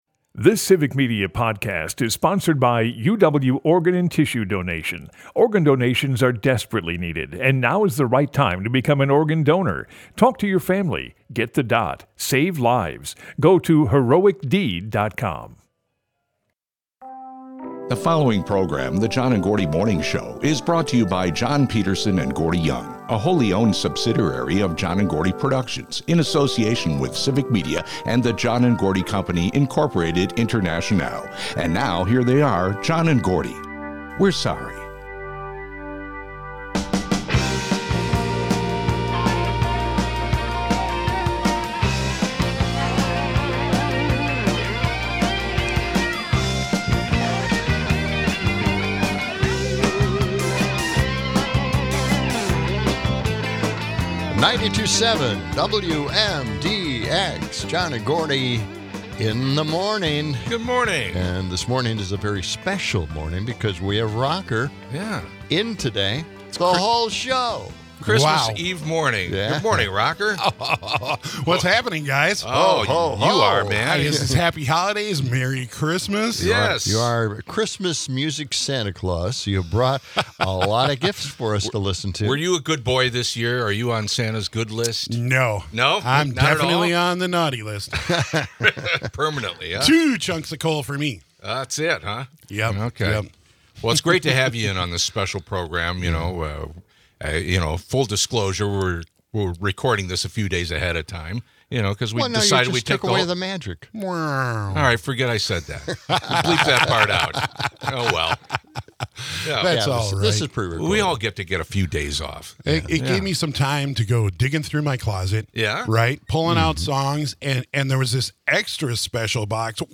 Alongside the music, the guys look back at some of their favorite Christmas comedy bits from over the years.